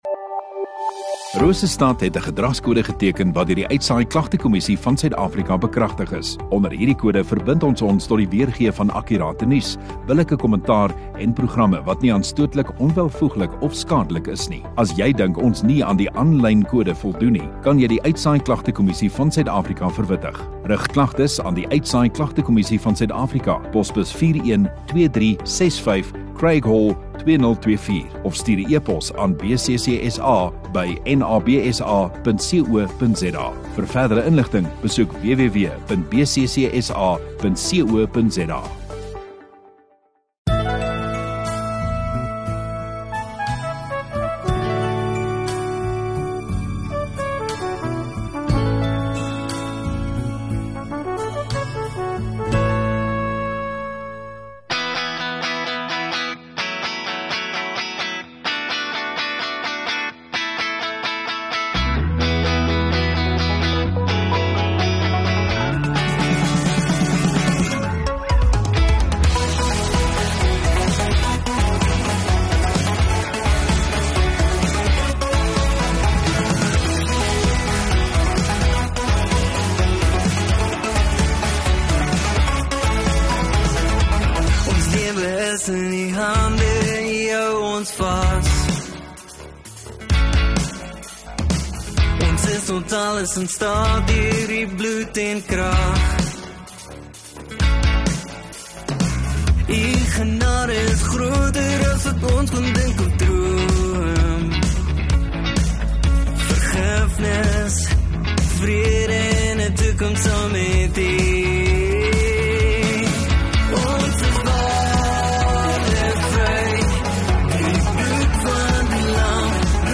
19 Oct Saterdag Oggenddiens